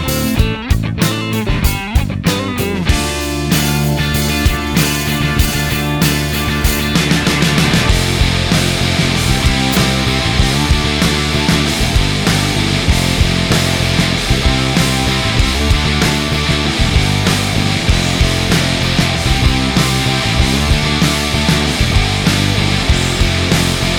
no Backing Vocals Rock 4:42 Buy £1.50